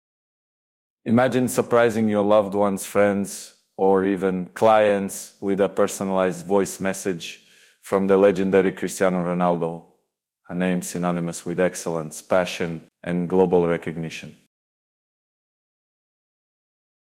Our service uses state-of-the-art voice synthesis technology to create a lifelike replication of Cristiano Ronaldo’s voice.
The result is a realistic and authentic voice message that resonates with Ronaldo’s iconic charm and unmistakable accent.
Our advanced AI ensures that every nuance of Ronaldo’s voice is captured, from his Portuguese accent to his intonation and cadence.
A: No, the voice is generated using advanced AI technology but is highly realistic and authentic.
cristiano-ronaldo-voice-message.mp3